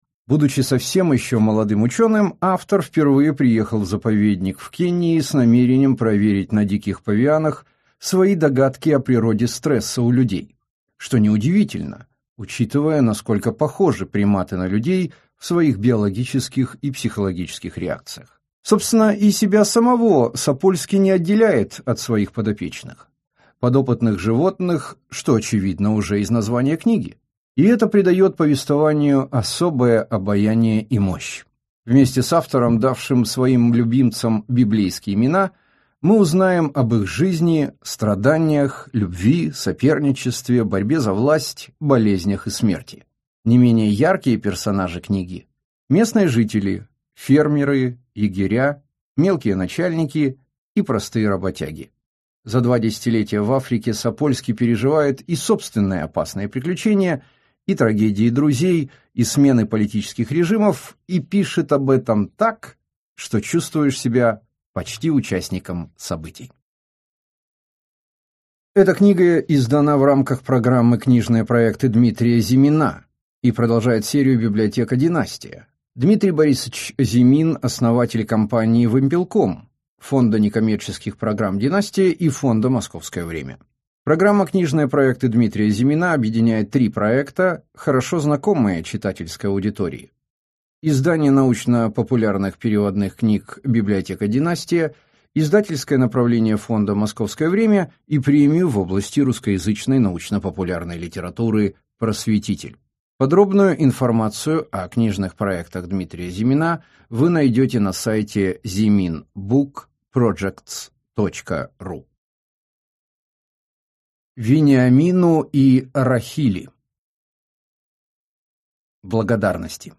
Аудиокнига Записки примата: Необычайная жизнь ученого среди павианов | Библиотека аудиокниг